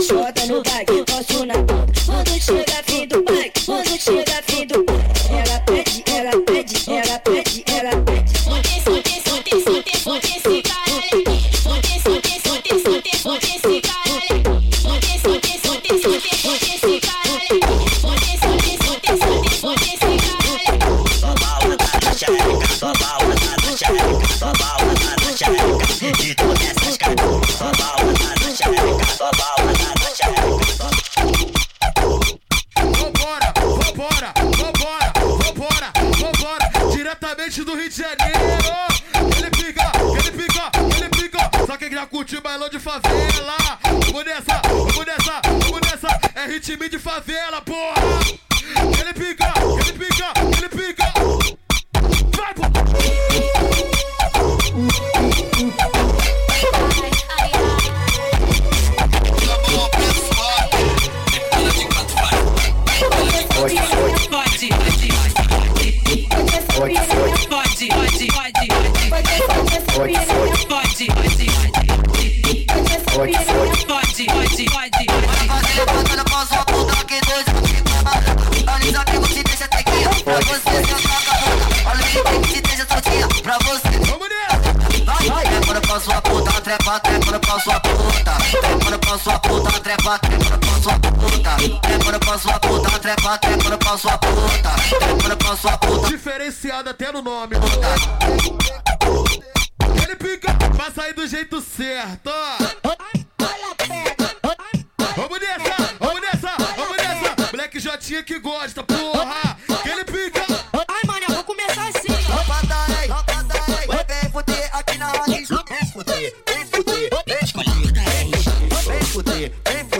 com o som mais acelerado e demente do baile funk brasileiro